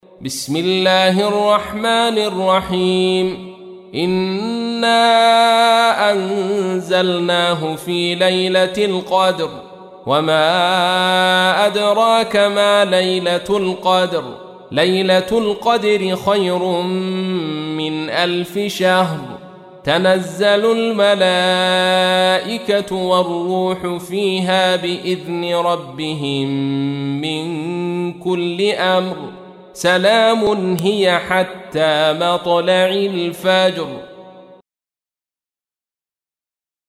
تحميل : 97. سورة القدر / القارئ عبد الرشيد صوفي / القرآن الكريم / موقع يا حسين